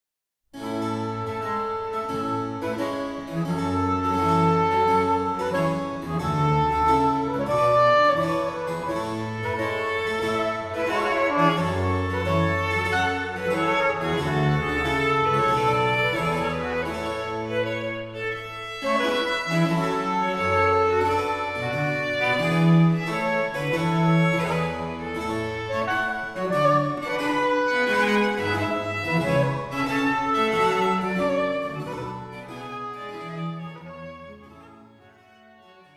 Triosonaten